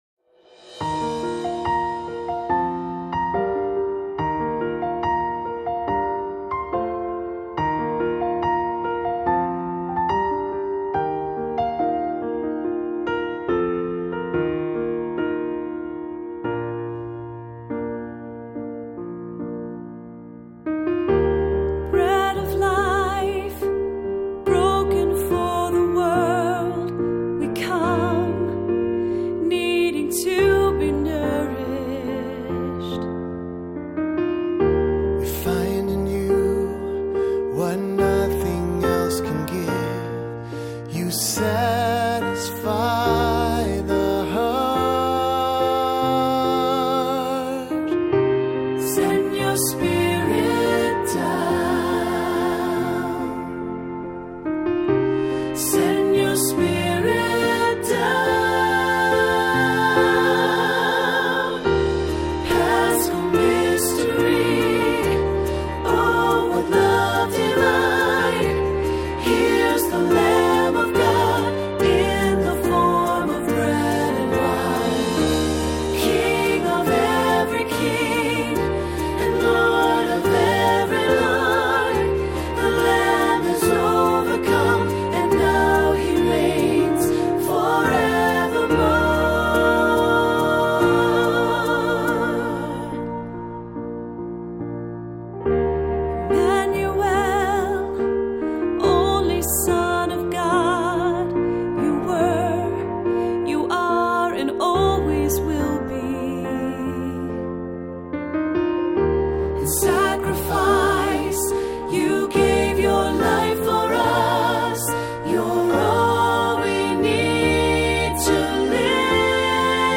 Voicing: Cantor, assembly,SATB